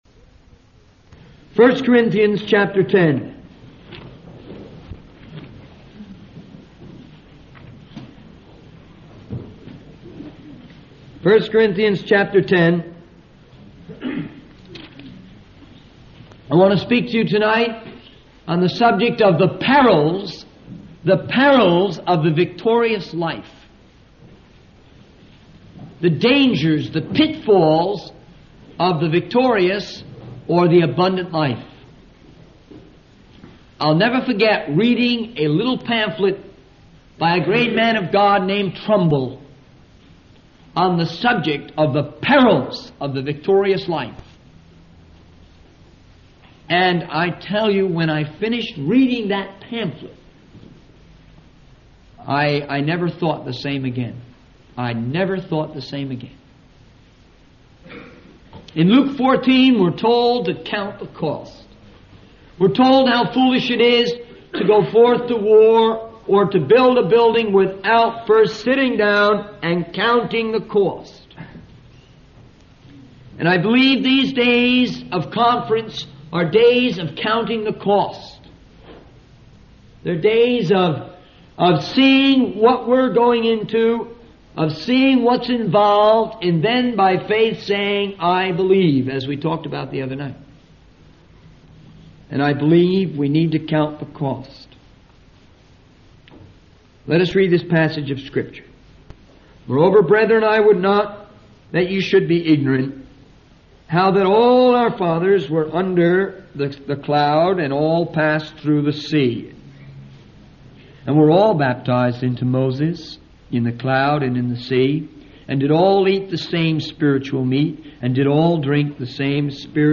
In this sermon, the preacher discusses the shocking passage of Scripture in 1 Corinthians where 23,000 people fell into immorality in one day.